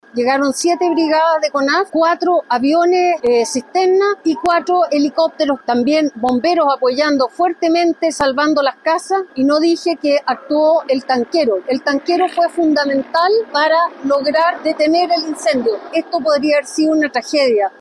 Al respecto, la Directora Ejecutiva de Conaf, Aida Baldini, entregó detalles de los recursos que fueron desplegados por la corporación, entre ellas brigadas y aviones.